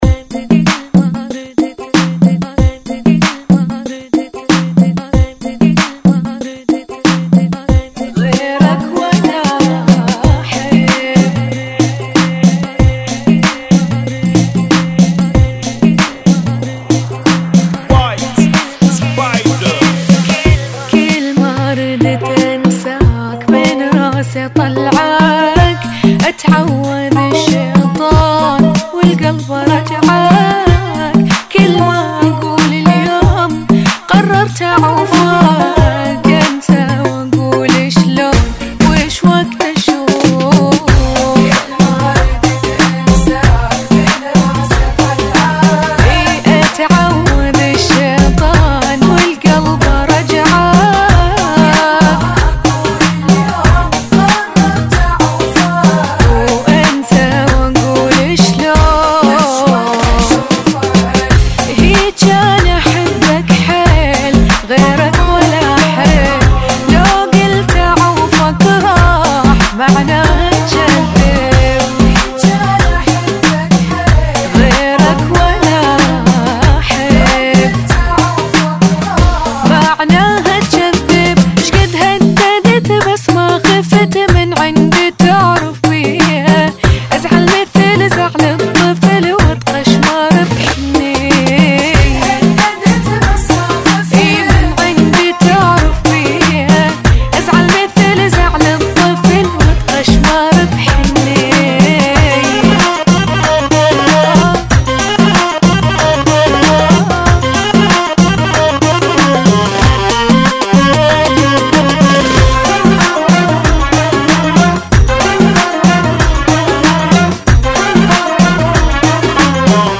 94 Bpm
Funky